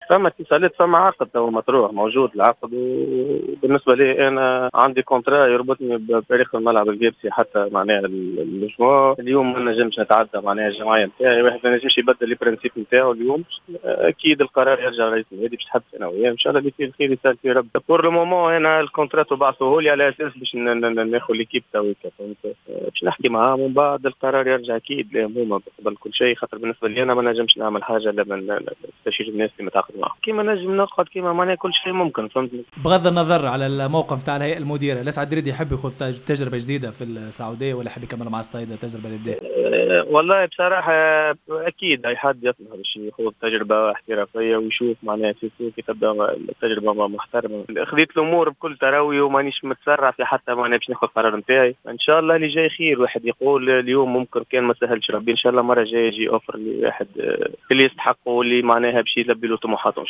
في تصريح لجوهرة اف ام